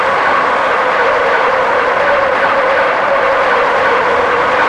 road_skid_int.wav